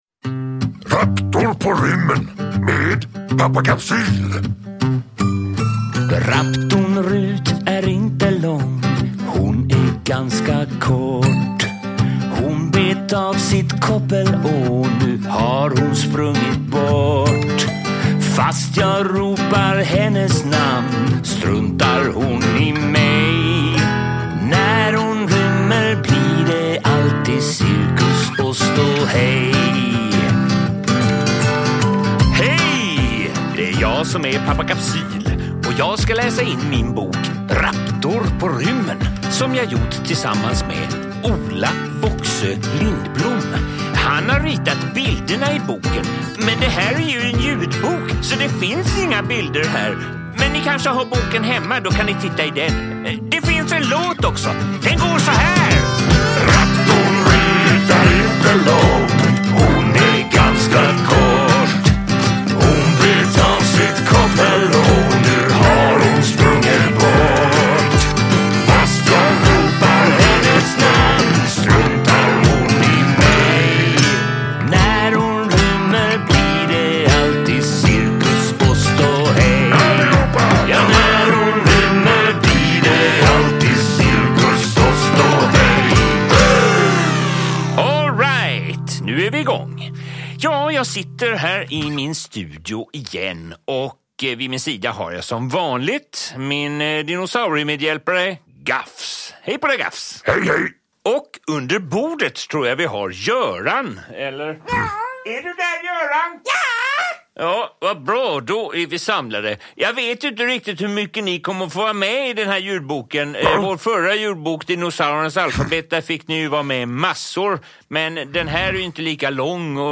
Raptor på rymmen – Ljudbok